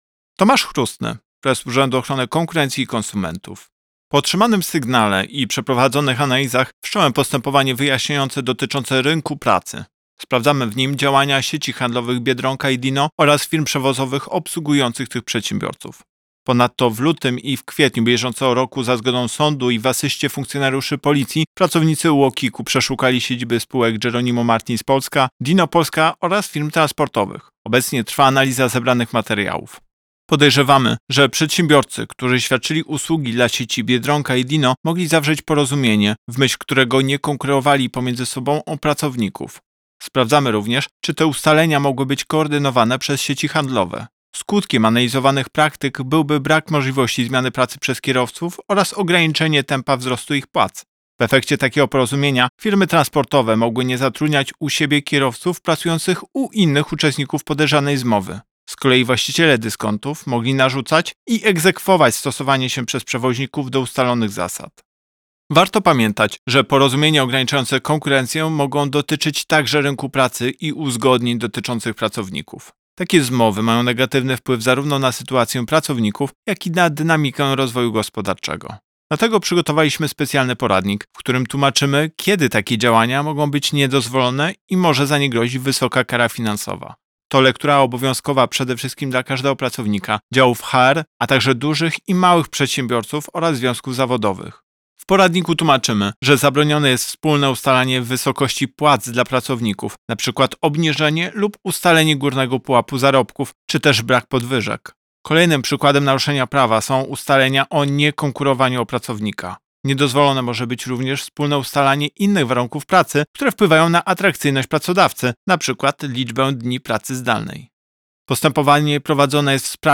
Wypowiedź Prezesa UOKiK Tomasza Chróstnego z 8 lipca 2024 r..mp3